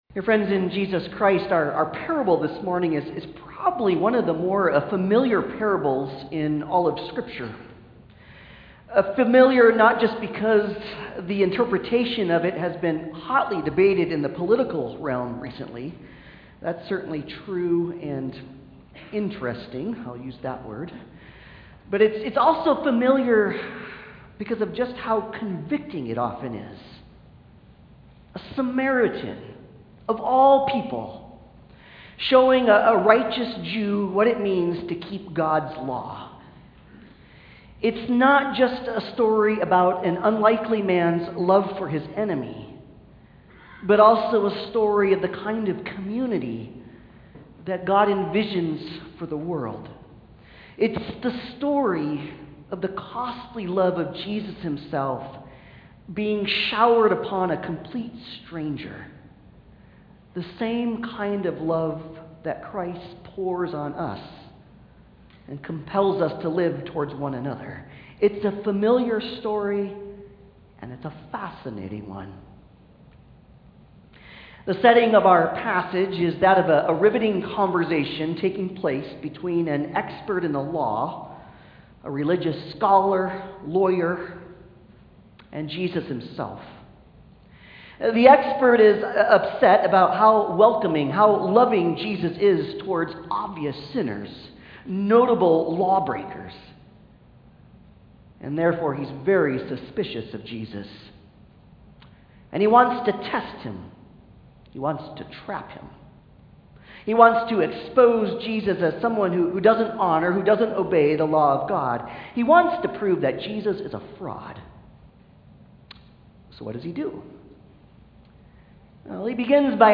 Passage: Luke 10:25-37 Service Type: Sunday Service